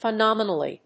音節phe･nom･e･nal･ly発音記号・読み方fɪnɑ́mən(ə)li|-nɔ́-